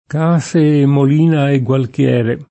k#Se e mmol&na e ggUalkL$re] (G. Villani); con grandissima forza e con non piccola utilità del signore due mulina volgea [